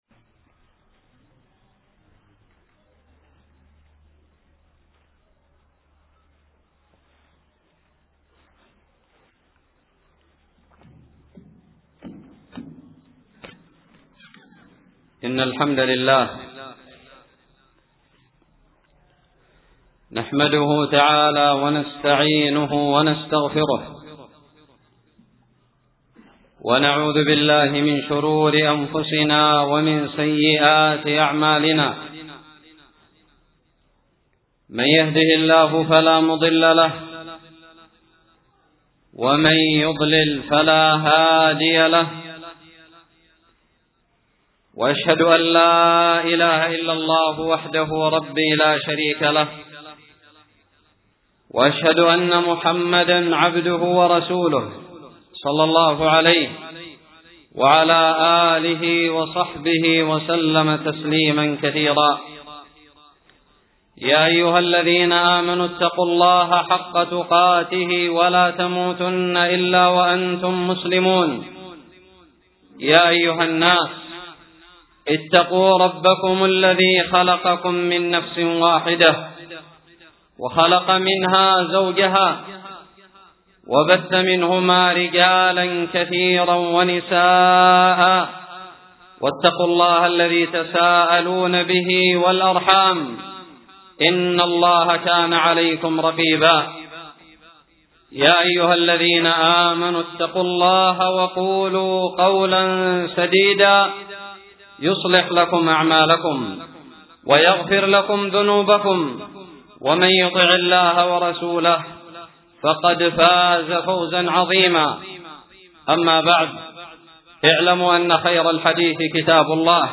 خطب الجمعة
ألقيت بدار الحديث السلفية للعلوم الشرعية بالضالع في 25 جمادى الآخرة 1438هــ